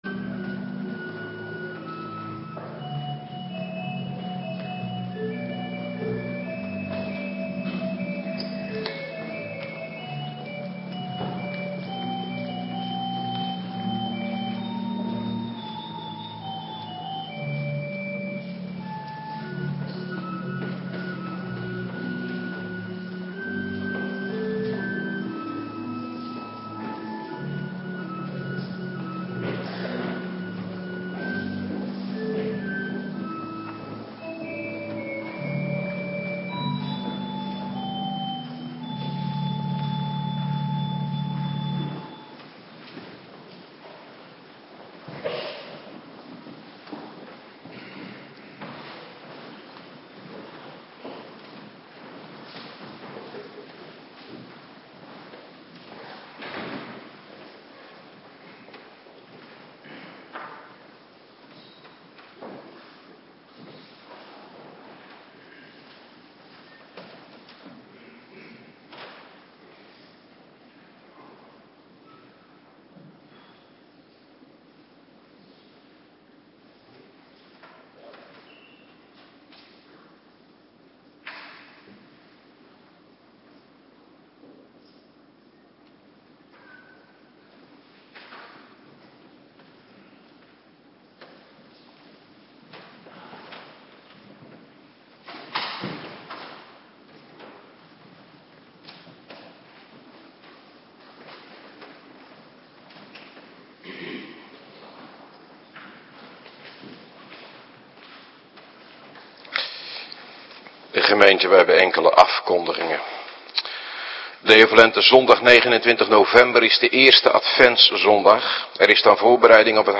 Morgendienst - Cluster 2
Locatie: Hervormde Gemeente Waarder